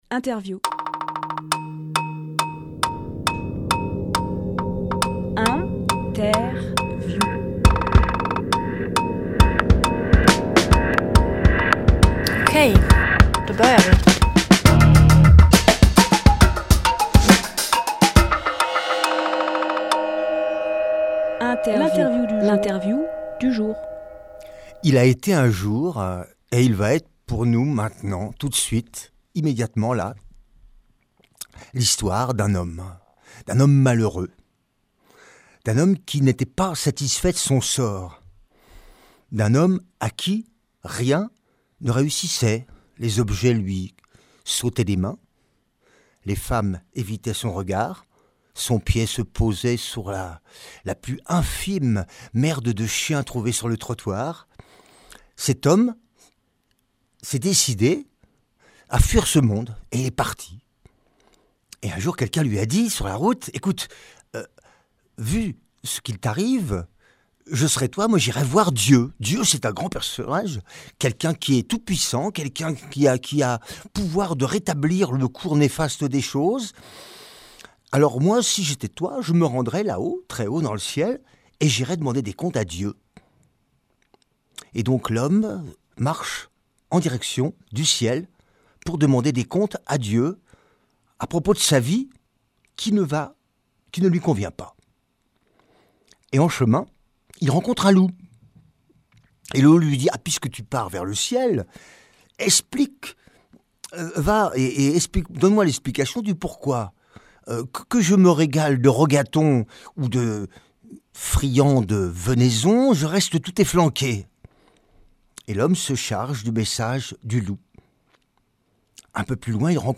Emission - Interview
Lieu : Studio RDWA